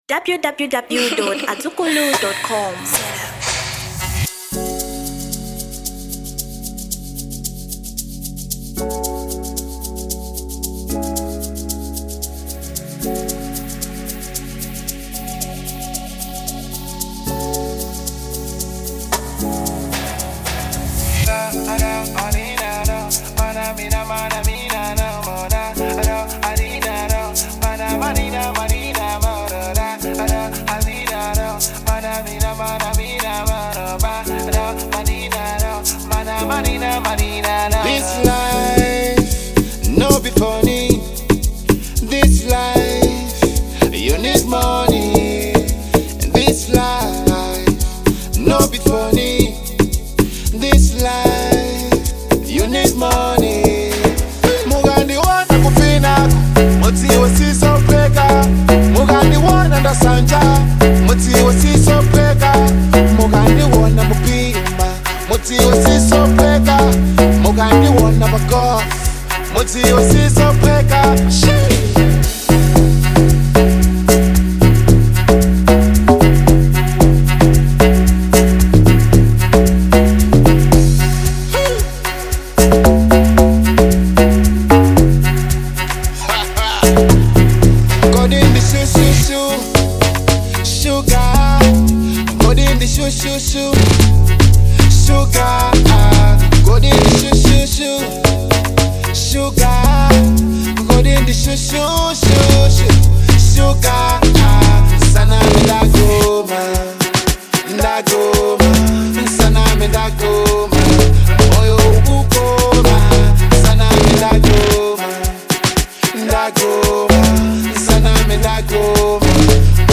Genre Amapiano